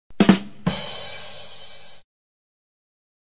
badumtiss.ogg